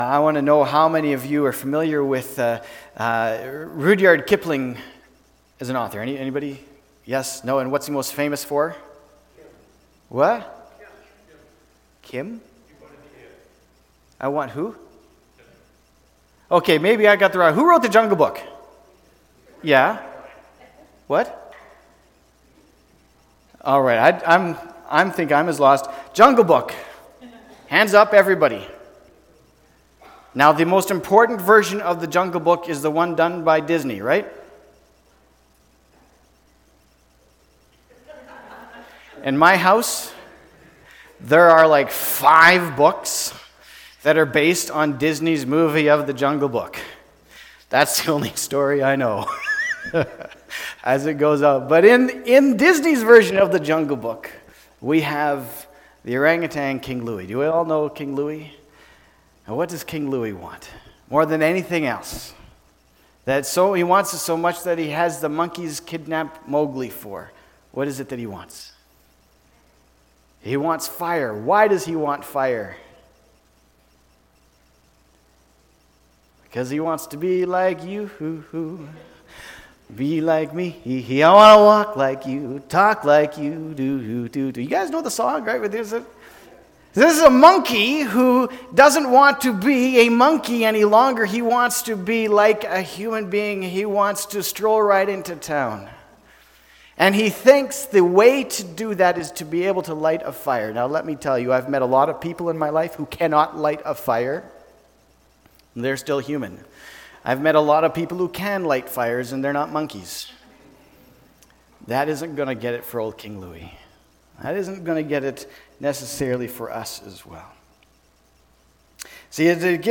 october-27-sermon.mp3